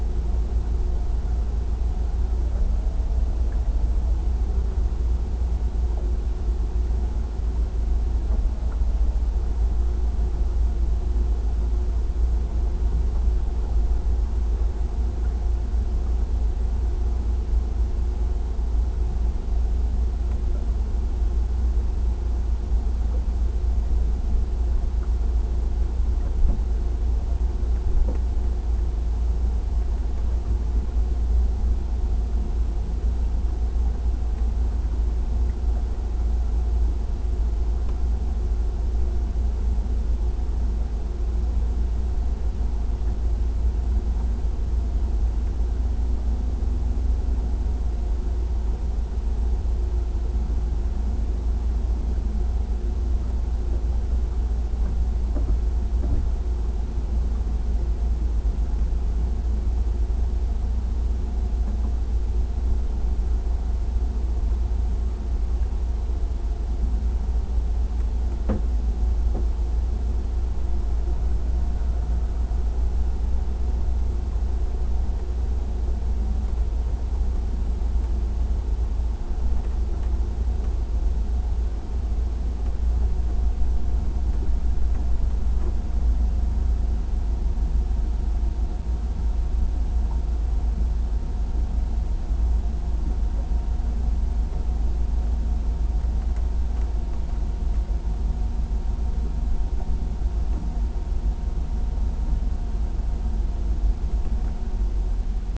boatSound.wav